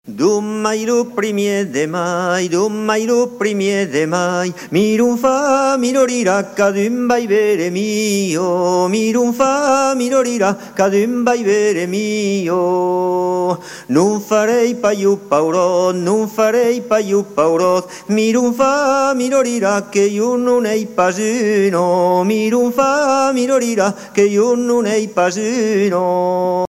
circonstance : quête calendaire ; circonstance : mai ;
Genre énumérative